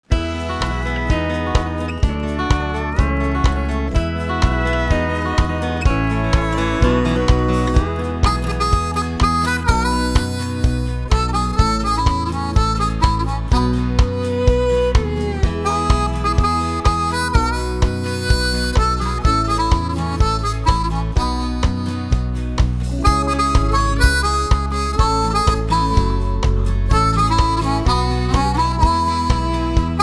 Singing Call
Inst